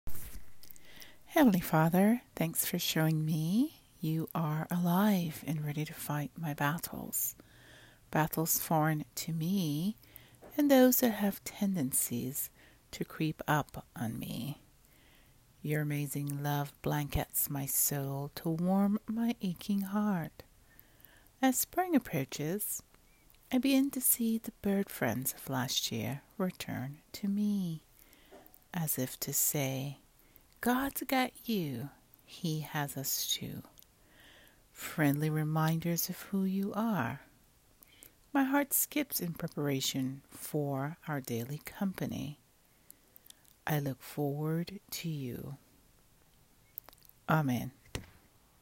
Listen to me read this prayer: